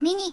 Added Zundamon voicepack